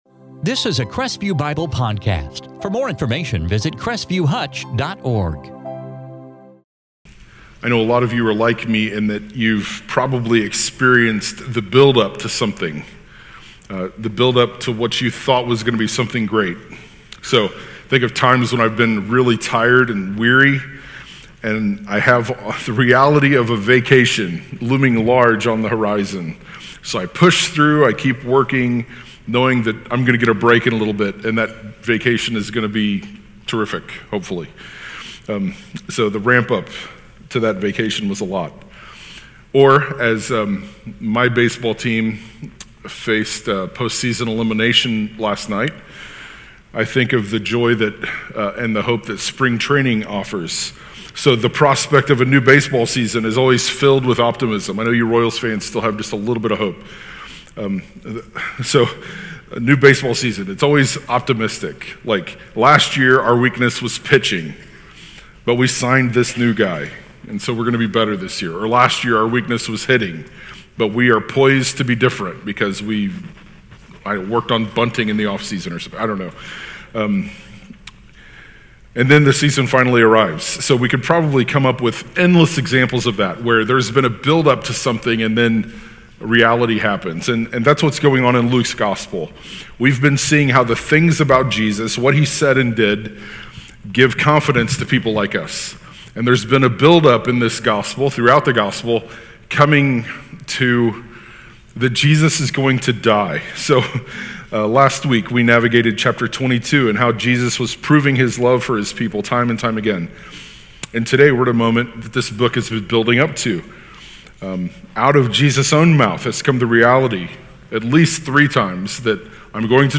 2024 Gospel of Luke Luke 23:1-56 In this sermon from Luke 23